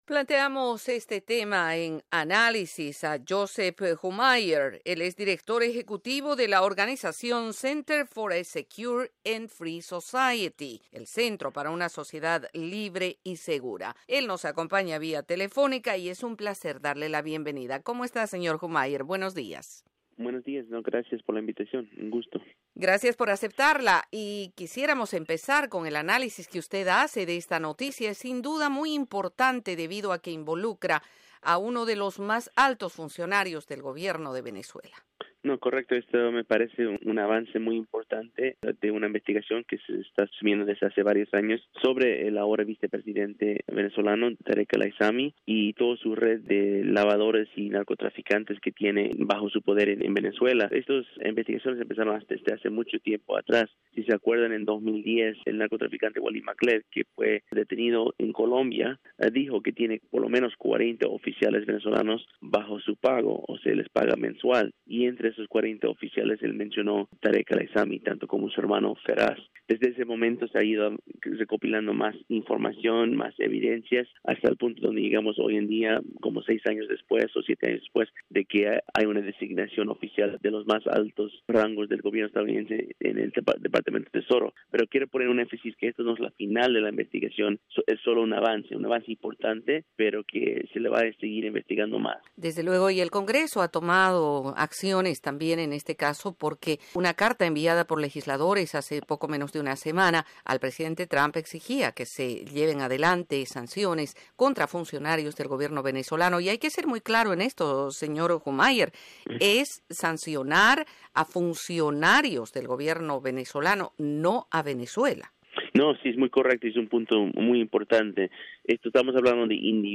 Colaboración con la entrevista